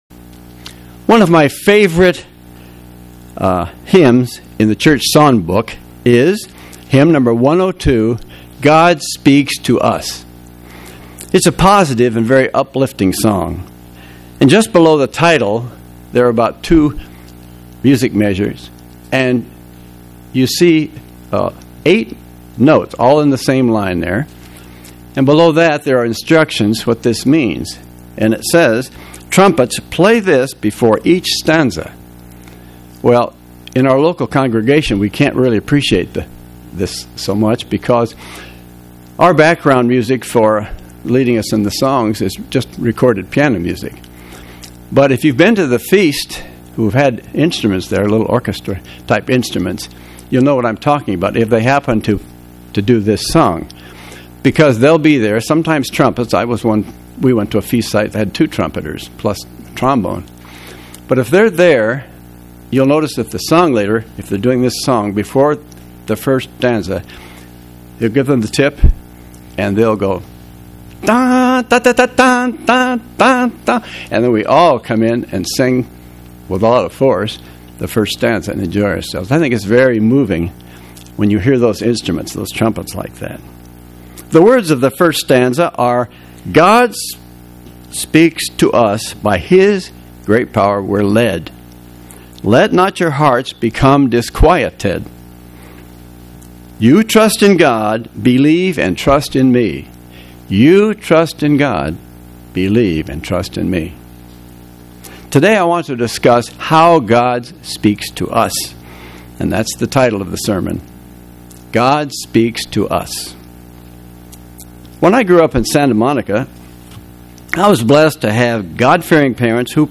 Given in Atlanta, GA
UCG Sermon Studying the bible?